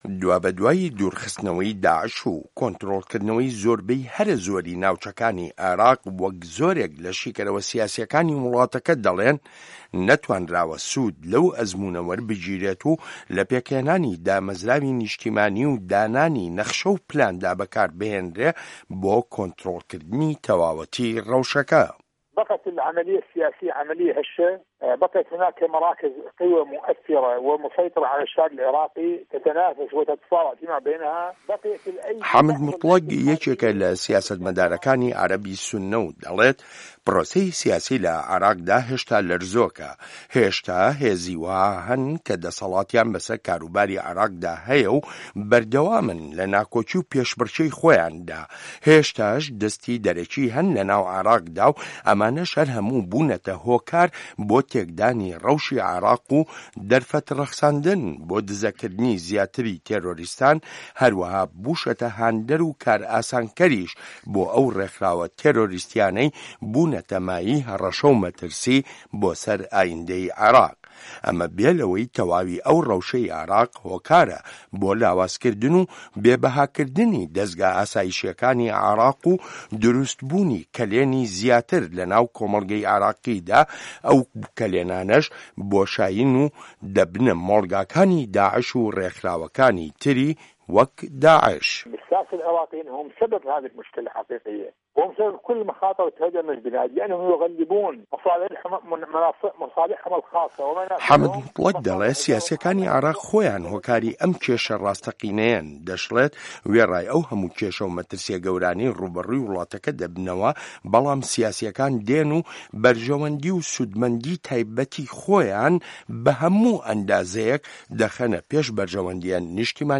ڕاپۆرت لەسەر بنچینەی لێدوانەکانی حامد موتڵەگ